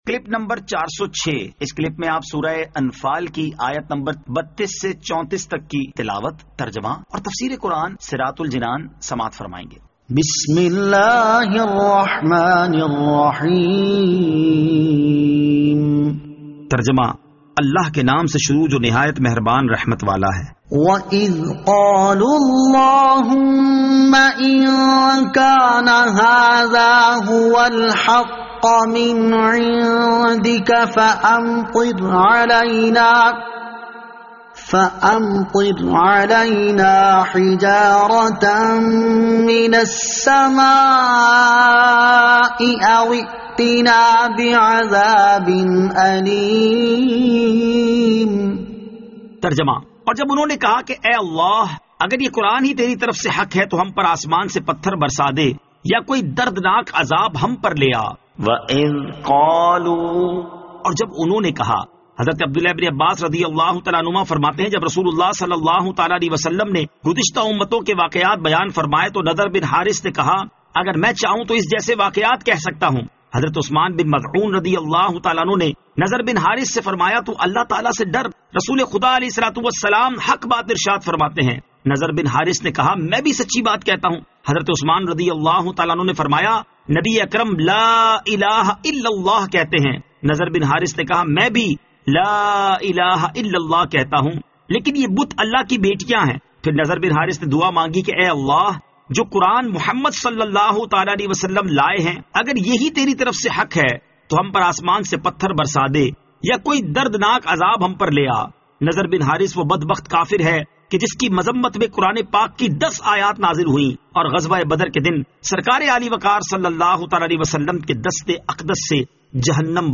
Surah Al-Anfal Ayat 32 To 34 Tilawat , Tarjama , Tafseer